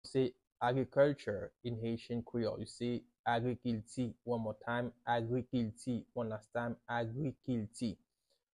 “Agriculture” in Haitian Creole – “Agrikilti” pronunciation by a native Haitian teacher
“Agrikilti” Pronunciation in Haitian Creole by a native Haitian can be heard in the audio here or in the video below:
How-to-say-Agriculture-in-Haitian-Creole-–-Agrikilti-pronunciation-by-a-native-Haitian-teacher.mp3